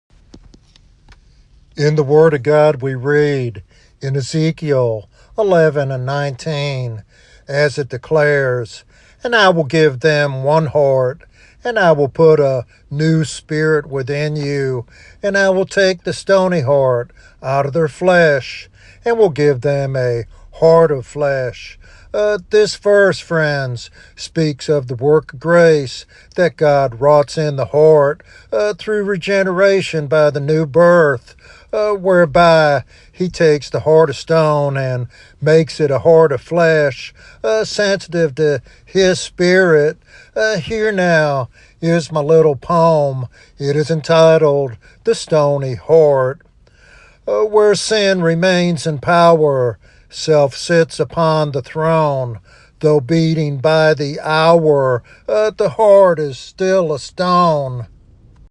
This sermon encourages believers to embrace God's regenerating power and live sensitively to His Spirit.